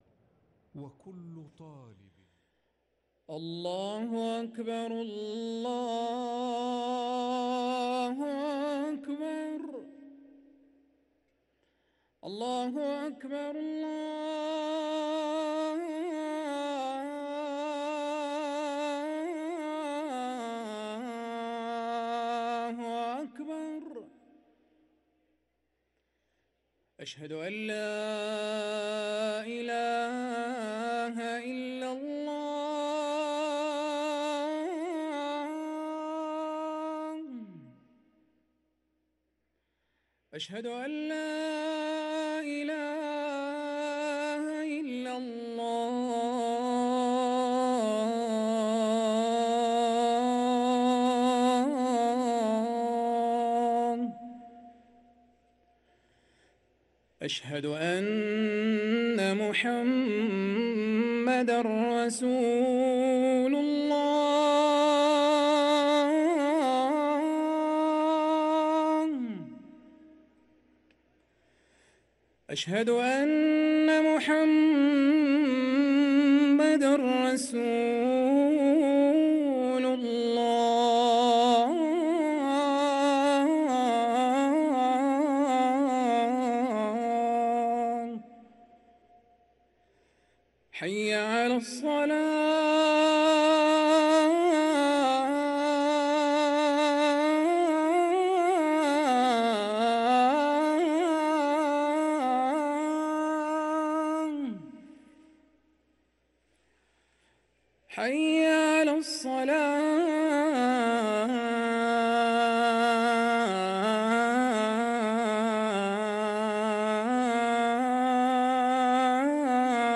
ركن الأذان